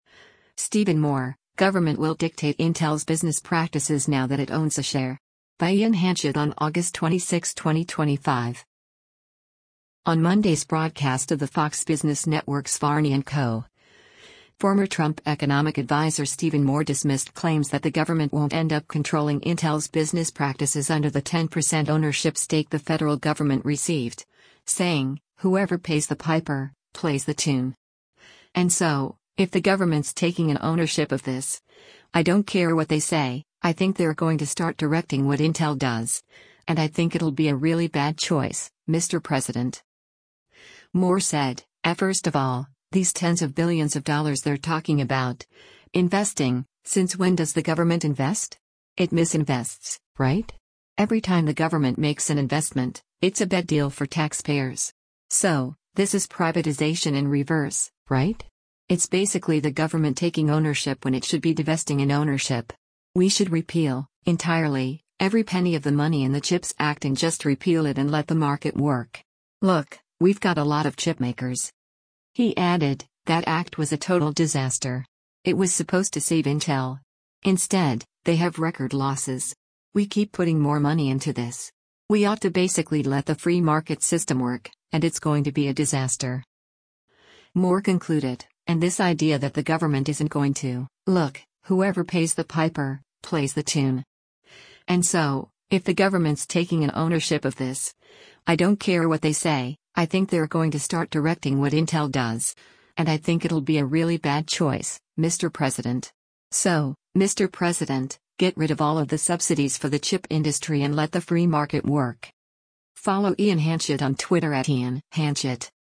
On Monday’s broadcast of the Fox Business Network’s “Varney & Co.,” former Trump Economic Adviser Stephen Moore dismissed claims that the government won’t end up controlling Intel’s business practices under the 10% ownership stake the federal government received, saying, “whoever pays the piper, plays the tune. And so, if the government’s taking an ownership of this, I don’t care what they say, I think they’re going to start directing what Intel does, and I think it’ll be a really bad choice, Mr. President.”